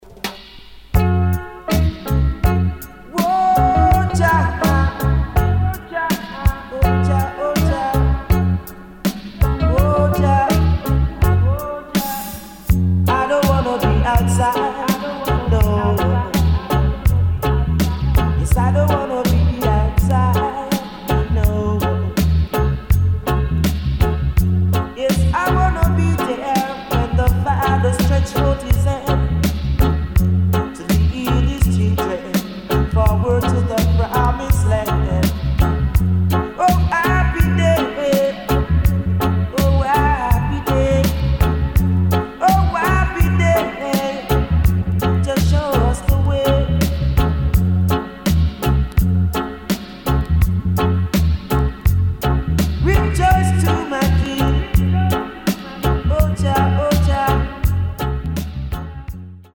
[ REGGAE | DUB ]